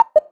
better button sound
deselect.wav